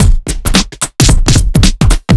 OTG_TripSwingMixD_110b.wav